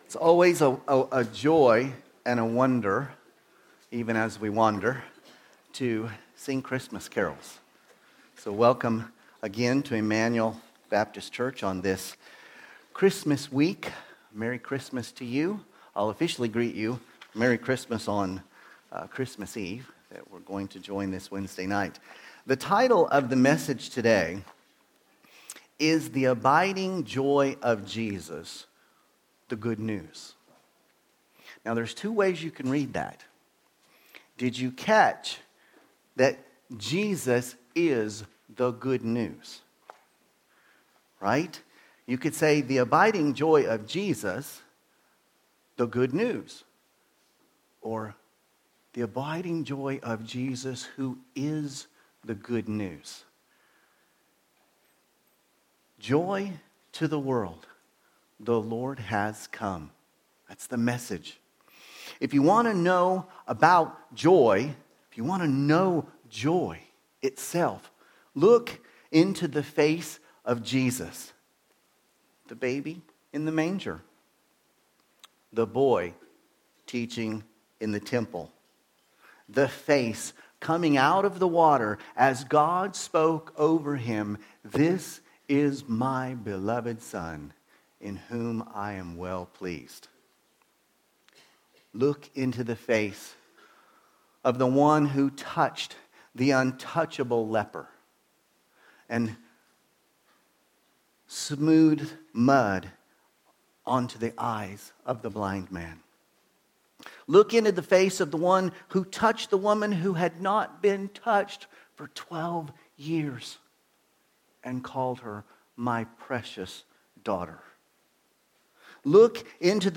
Sermons – Immanuel Baptist Church | Madrid